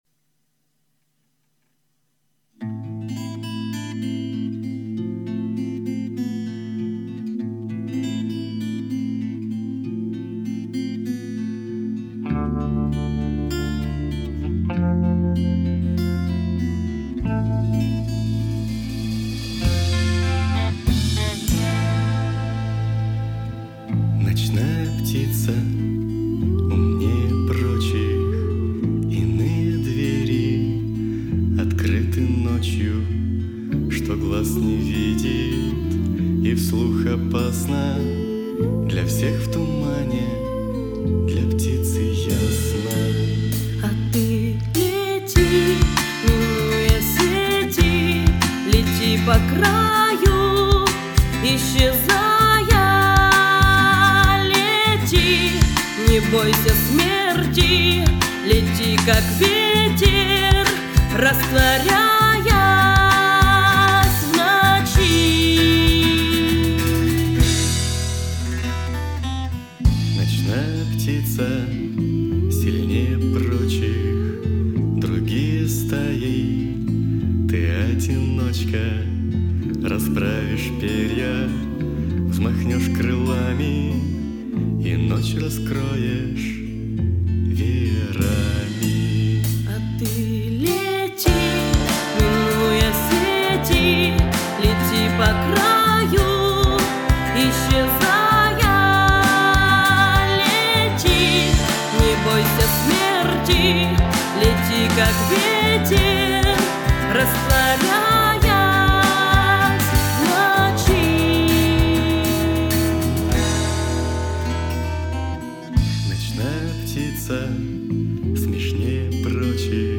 Акустик Рок , Русский Рок.
Записывал начинающих музыкантов из нашего города.
Ерунда с пространством, с громкостью, и с аранжировкой.